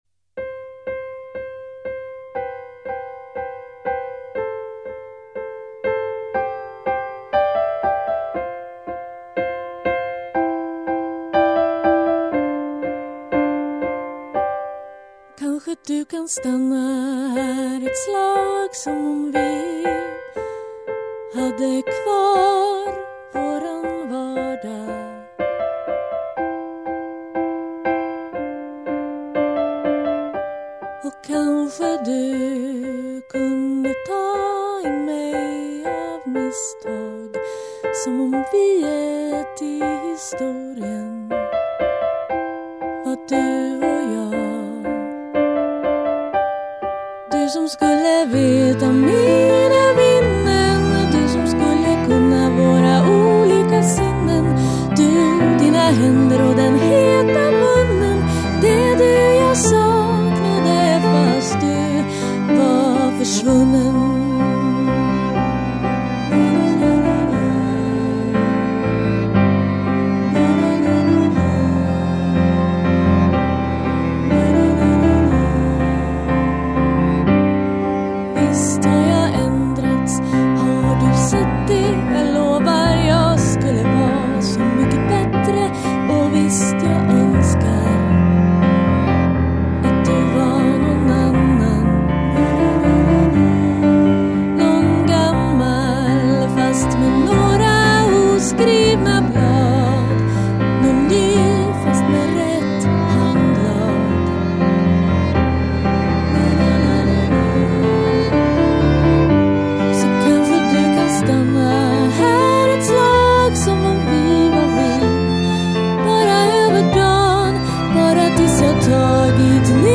piano, dragspel, sång
Saxofon
Fiol
Trumprogrammering & synth
Cello